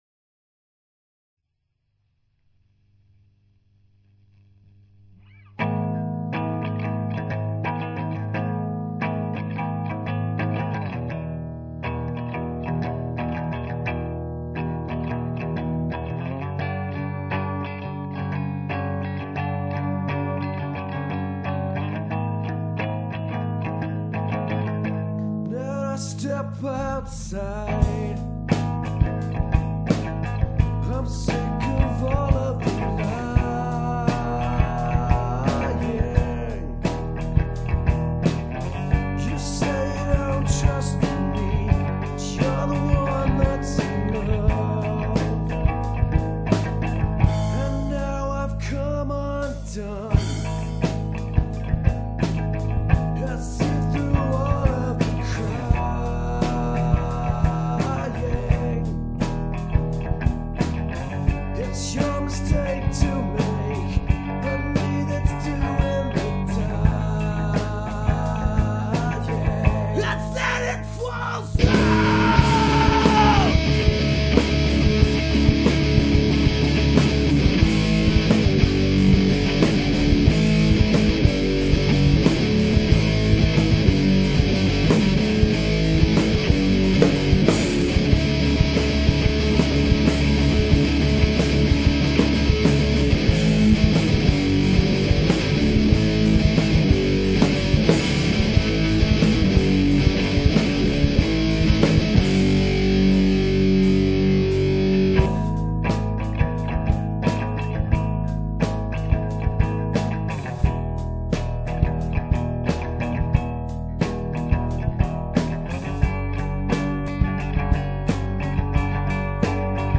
rock/metal